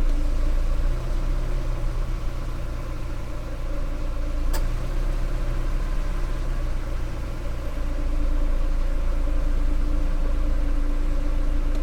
engine.ogg